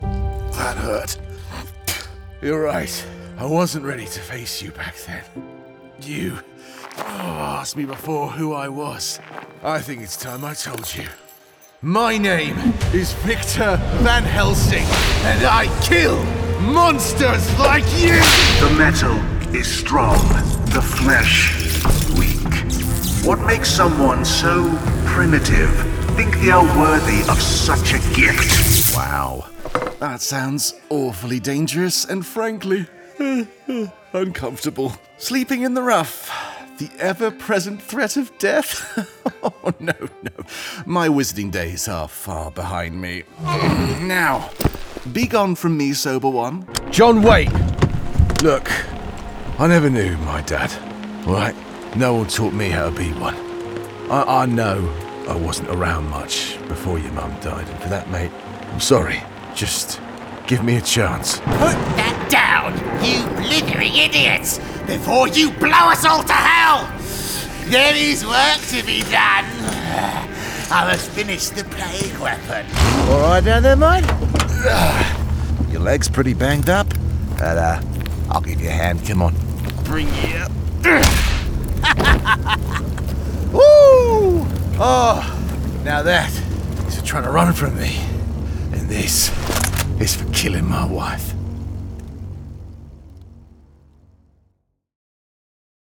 If you’re looking for a smooth British voice as well as other accents (If that’s your cup of tea), look no further.
GAMING 🎮
military
pirate